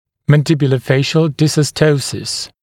[ˌmænˌdɪbjulə’feɪʃl ˌdɪsɔs’təusɪs][ˌмэнˌдибйулэ’фэйшл ˌдисос’тоусис]челюстно-лицевой дизостоз